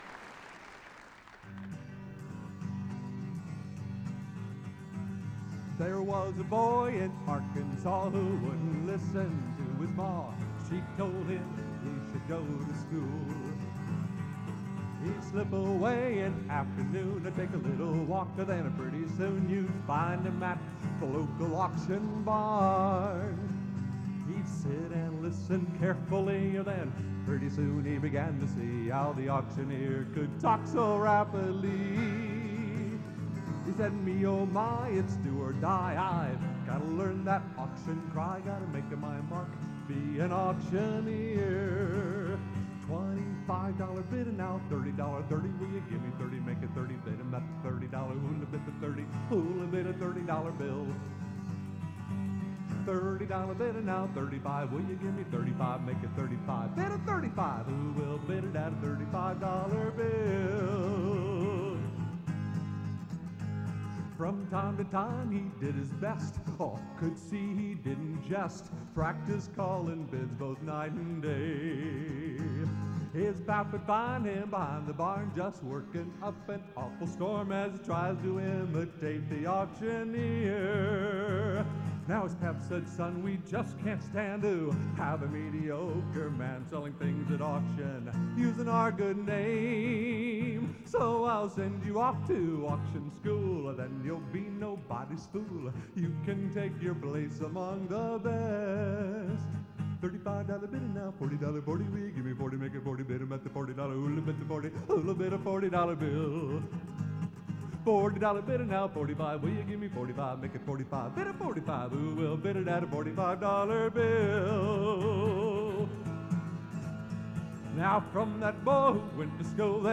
Collection: Centennial Celebration Concert 1993
I really enjoyed the comedy end of singing.